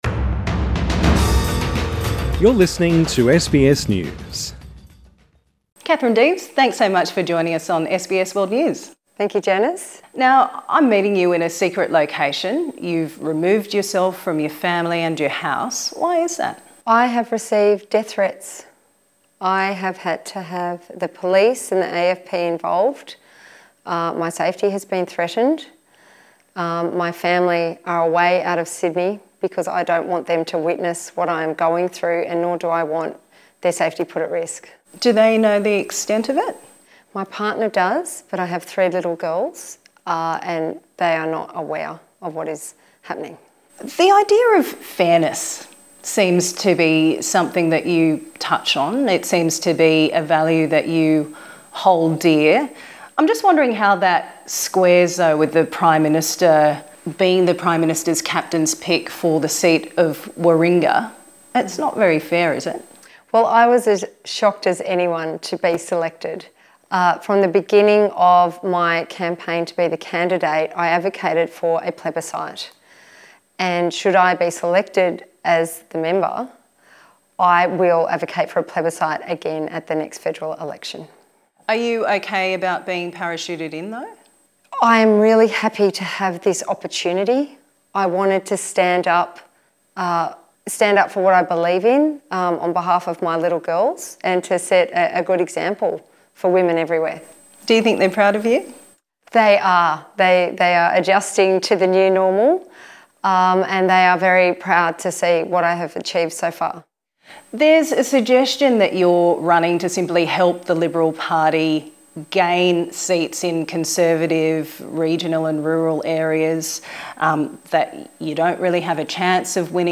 Katherine Deves speaks to SBS World News presenter Janice Petersen.